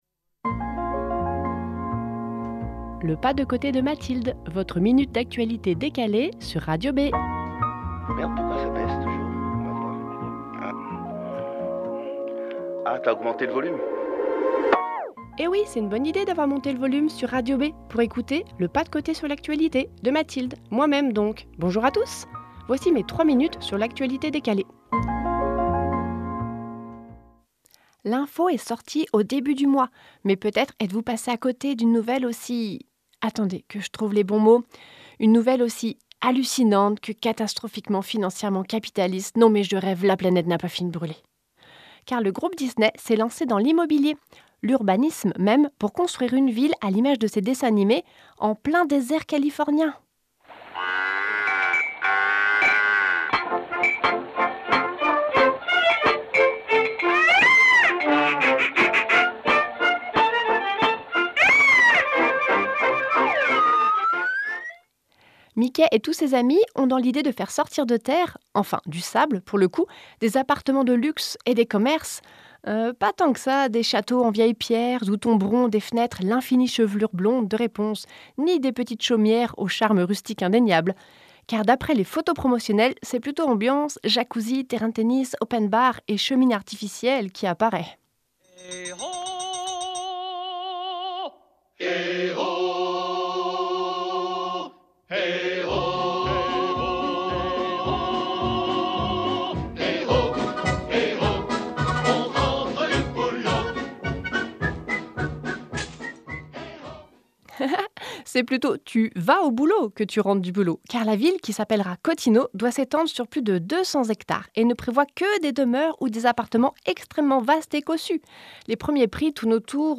En direct le jeudi à 7h55, 8h55, 12h55 (Le 2e et 4e jeudi du mois)